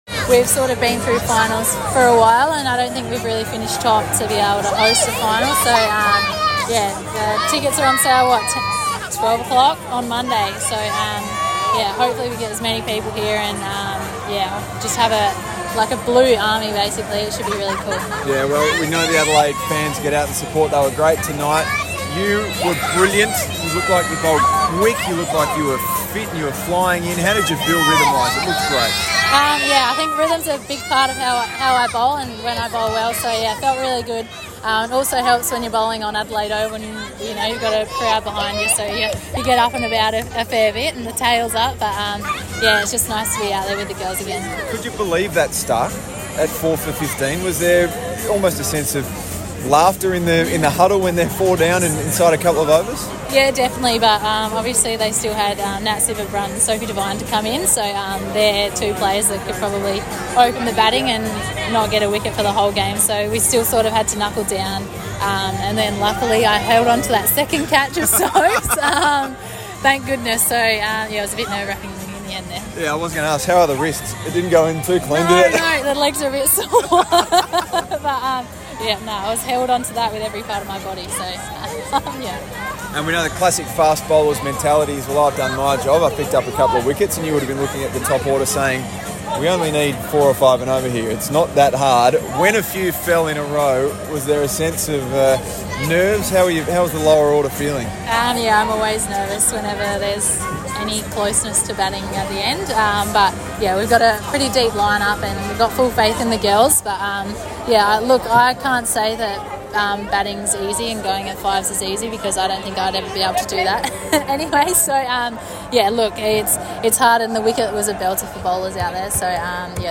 Darcie Brown spoke to media after the Adelaide Strikers five wicket win against the Perth Scorchers at Adelaide Oval.